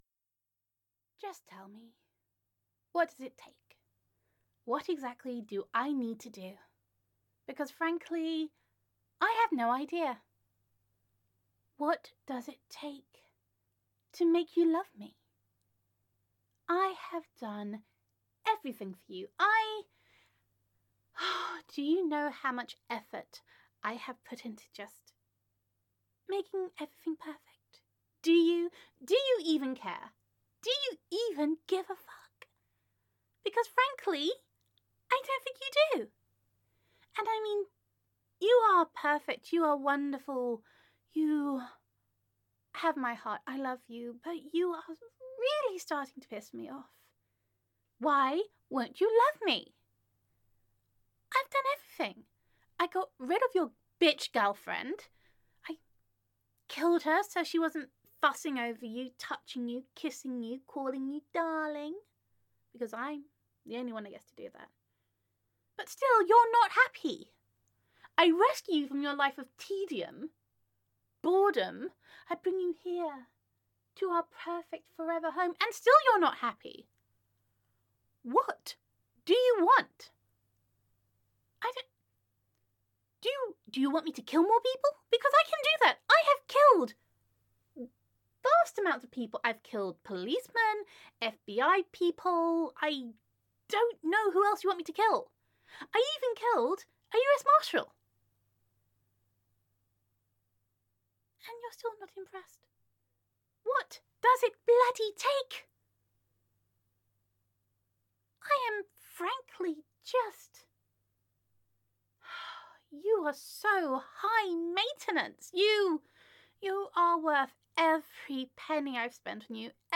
[F4A] High Maintenance [WHY WON’T YOU LOVE ME!?!!][WHAT DO YOU WANT FROM ME?!?][LOVE ME!!!][Temper Tantrum][Emotion Manipulation][Gender Neutral][Emotionally Unstable Yandere Roleplay]